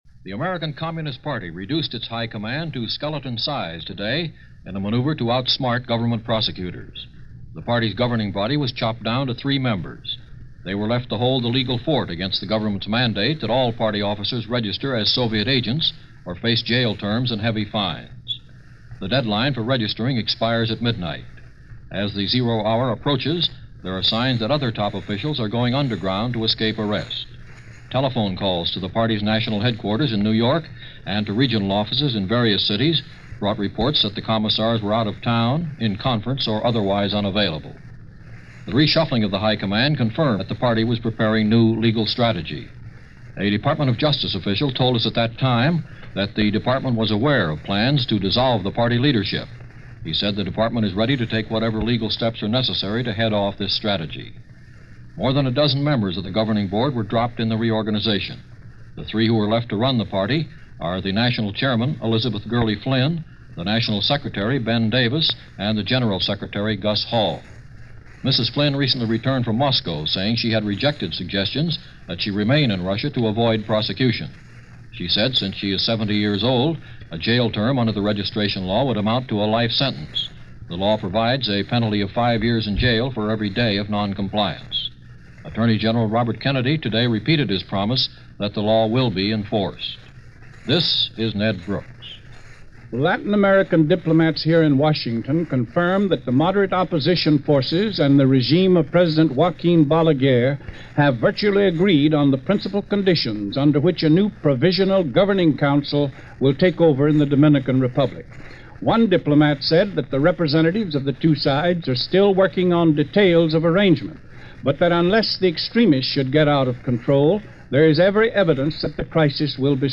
And that’s a small slice of what went on in this hackneyed world, as reported and summed up by a group of NBC Radio correspondents on this last day of November in 1961.